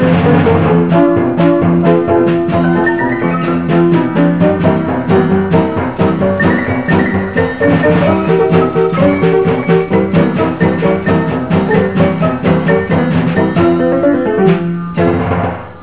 ST - Oak, grade 2 (4 instruments)